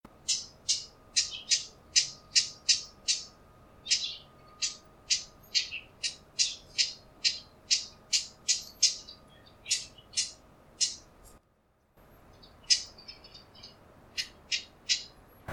A ．ウグイス（じなき）　B．ウグイス（さえずり）
uguisuzinaki_noizuzyokyo.mp3